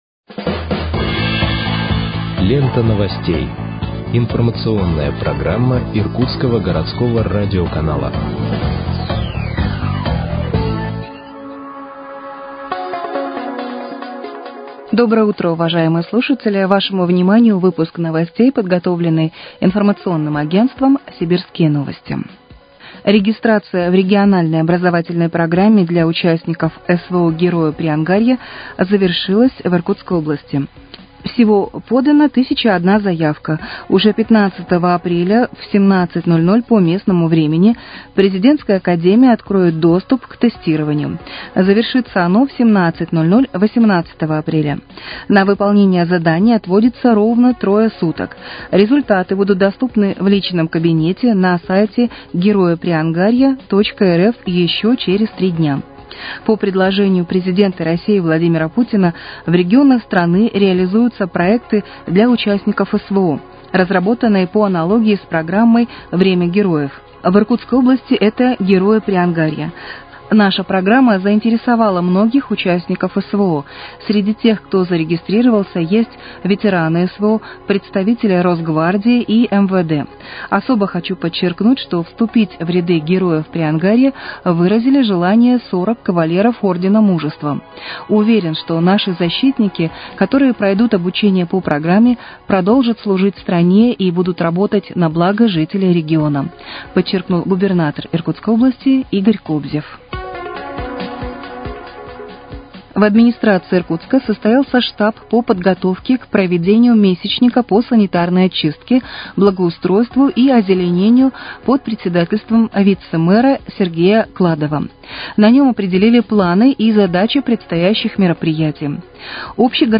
Выпуск новостей в подкастах газеты «Иркутск» от 17.04.2025 № 1